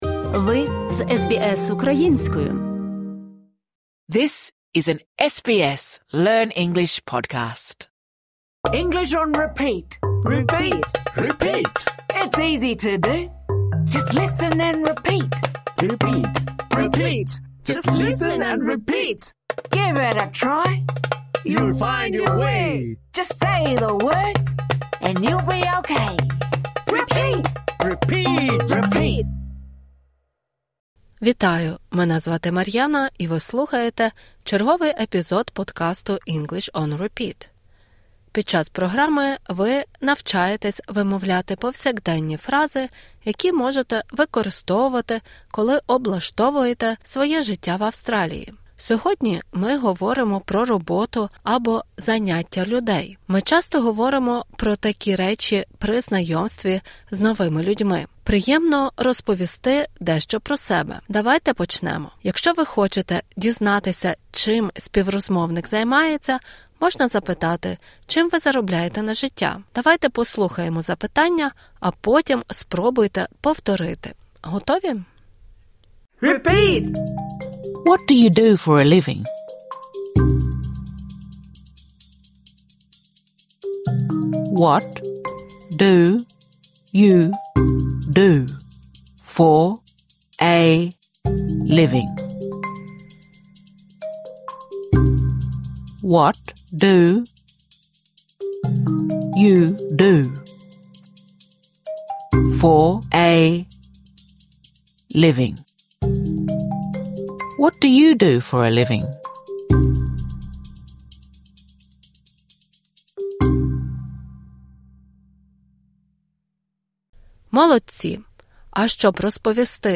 Цей урок призначений для початківців.